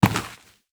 Dirt footsteps 19.wav